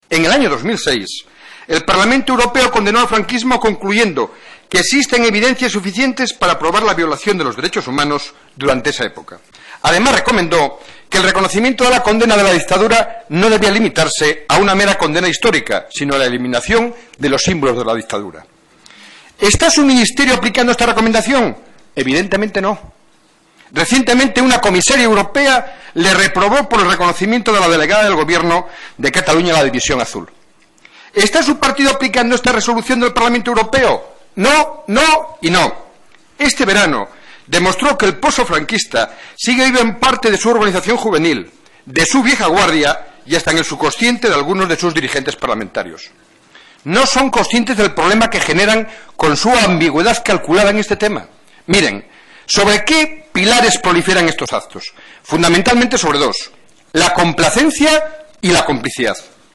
Fragmento de la intervención de Antonio Trevín en la comparecencia del ministro del Interior sobre lo sucedido en la librería Blanquerna el 11/09/2013